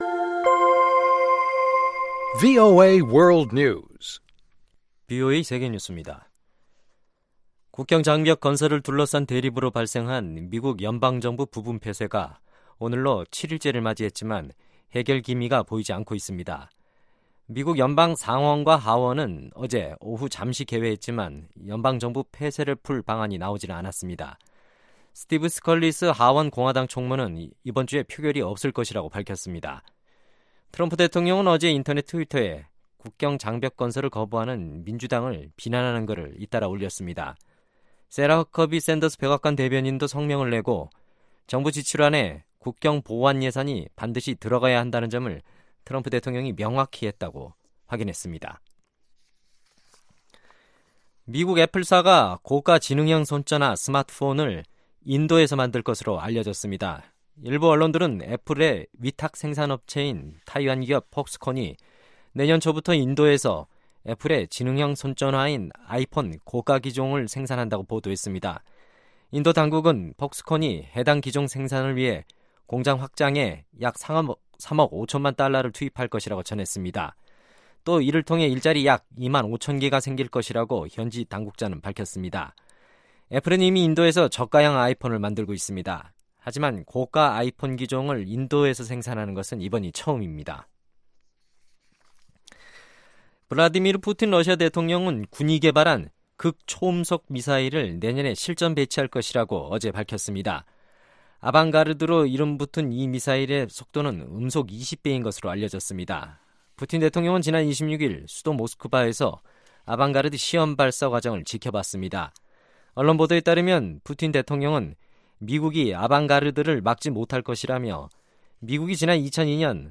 세계 뉴스와 함께 미국의 모든 것을 소개하는 '생방송 여기는 워싱턴입니다', 2018년 12월 28일 저녁 방송입니다. ‘지구촌 오늘’은 미 정부가 중국산 수입품 900여 개 품목에 관세를 면제해줬다는 소식, ‘아메리카 나우’에서는 연방 정부 부분 폐쇄 사태가 새해로 이어질 전망이라는 이야기를 전해드립니다. 연말을 맞아 지난 한 해를 돌아보는 특집 방송 ‘2018년 세계뉴스 결산’에서 는 전 세계를 뜨겁게 달궜던 사건, 사고 들을 정리했습니다.